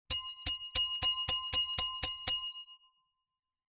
Звуки настольного звонка
Звонок на ресепшн отеля тревожный и дребезжащий